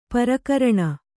♪ parikaraṇa